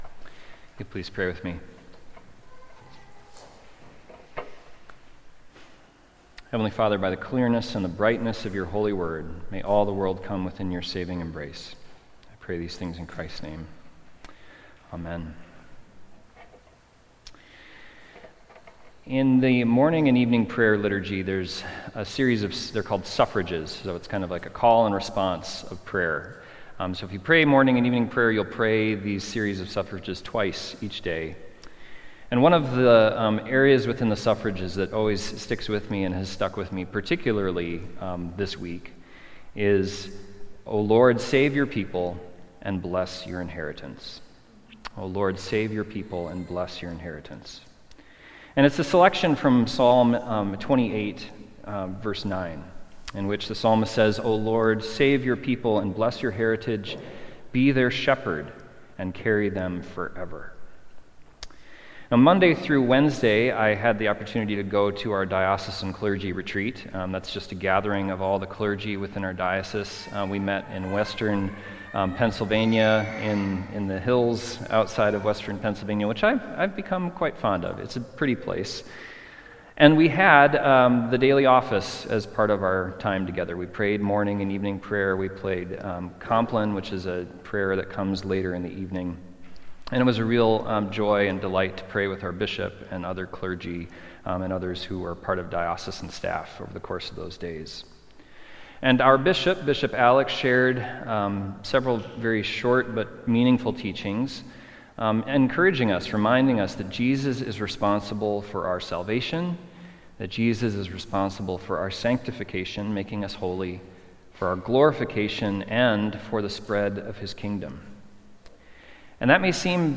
Sunday Worship–September 14, 2025
Sermons